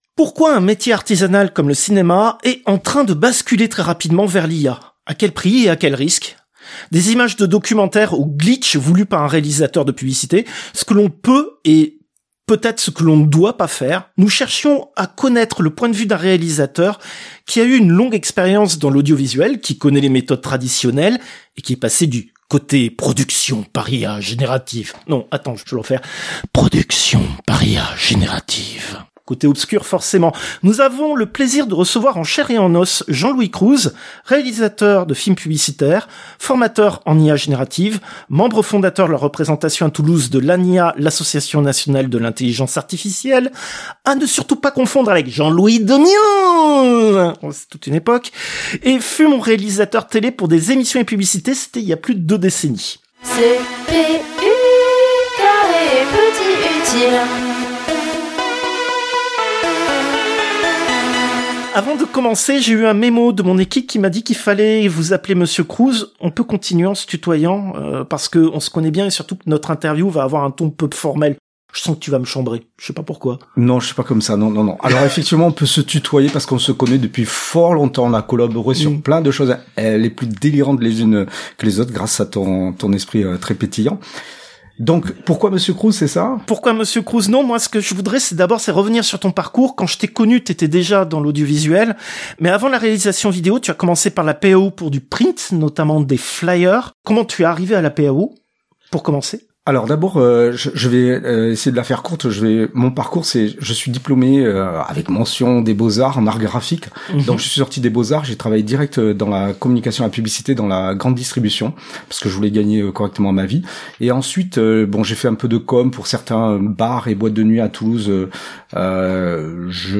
Interviewes